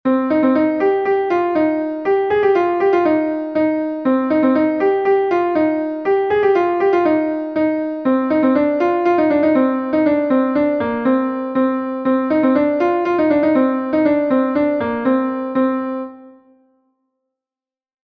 Gavotenn Ignel est un Gavotte de Bretagne